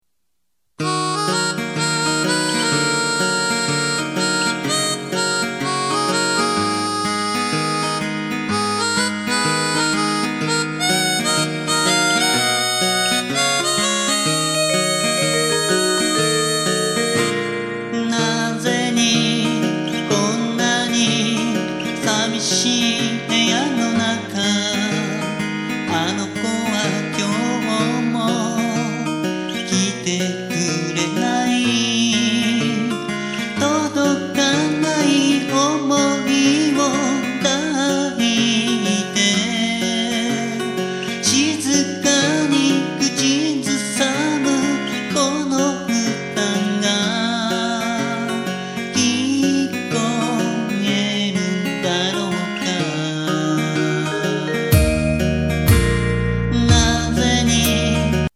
ワンコーラス　　ＭＰ３ファィル・・・897KB